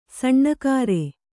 ♪ saṇṇa kāre